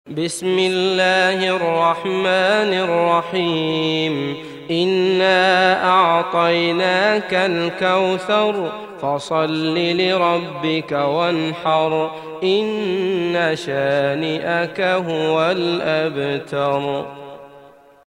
تحميل سورة الكوثر mp3 بصوت عبد الله المطرود برواية حفص عن عاصم, تحميل استماع القرآن الكريم على الجوال mp3 كاملا بروابط مباشرة وسريعة